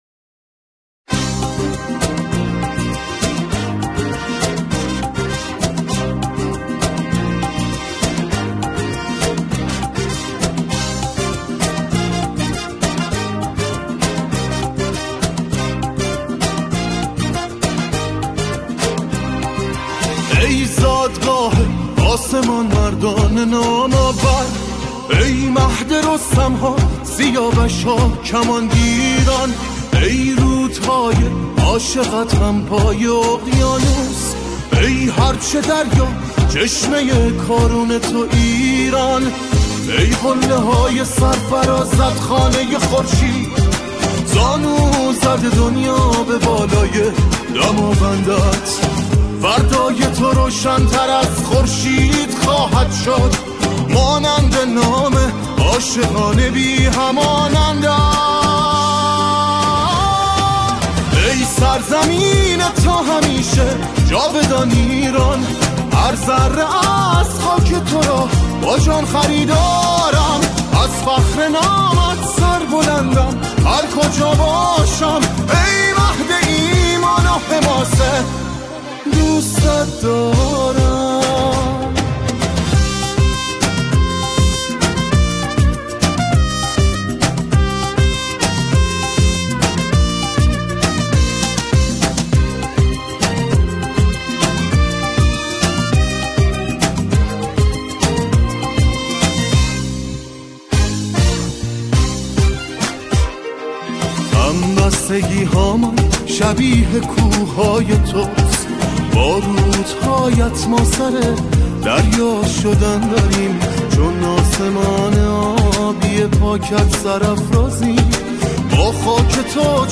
آنها در این قطعه، شعری را درباره ایران همخوانی می‌کنند.